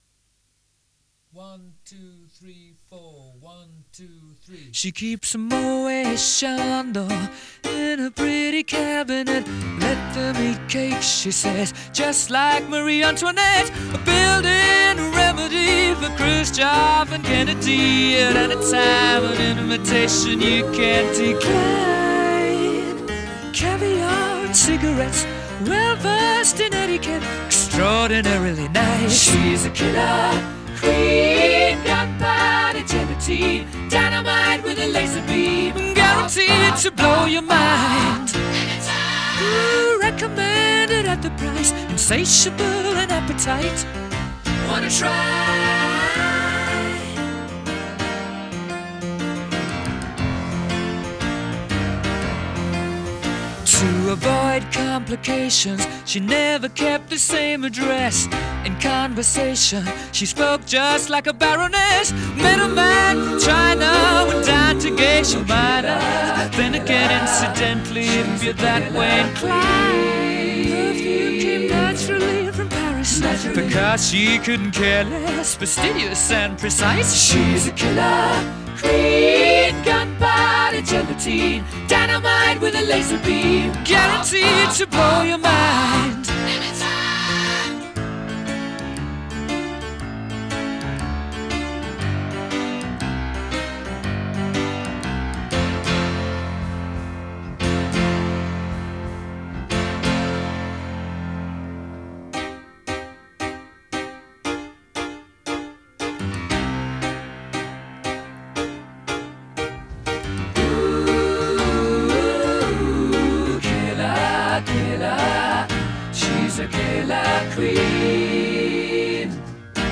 These are my mixes!!!
Only, Piano and Vocals